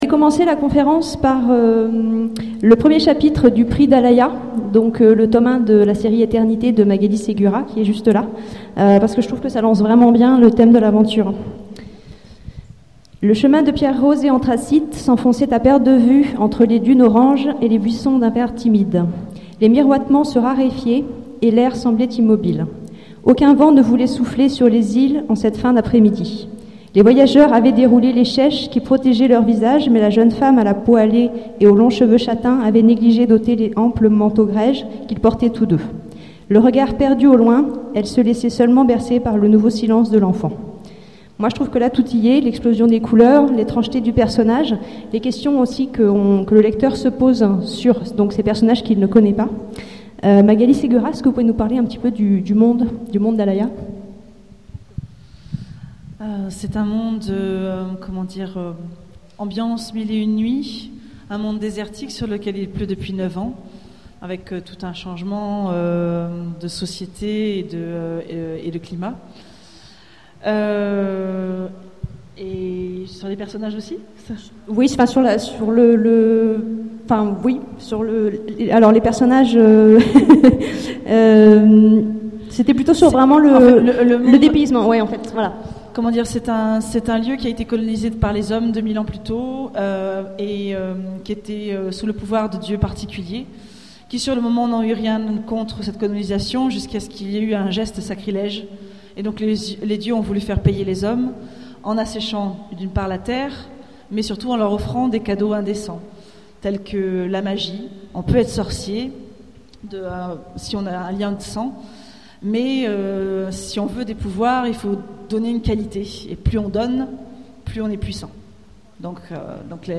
Imaginales 2014 : Conférence Roman d'aventures... Plaisir de lire!